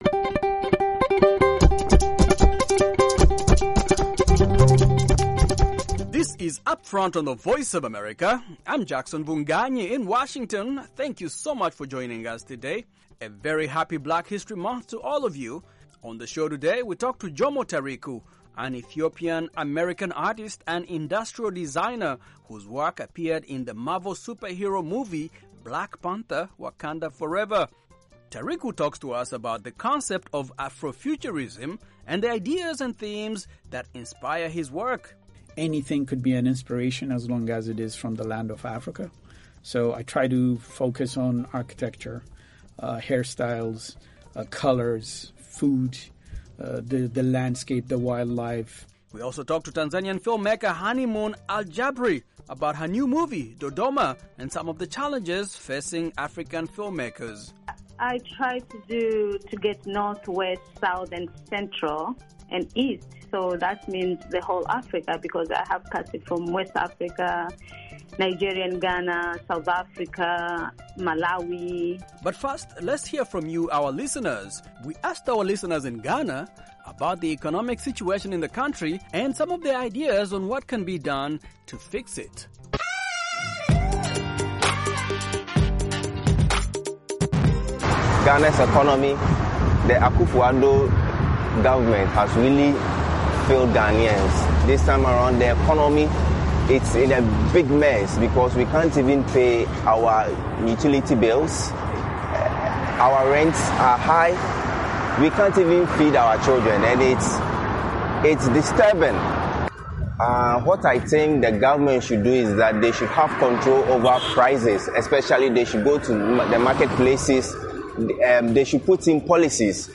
In our vox pop segment, Ghanaians speak on ways to help fix their economy.